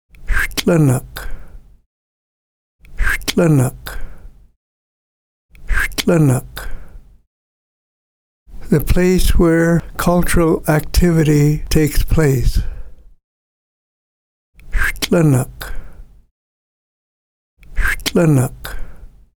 VAG-Pronunciation.mp3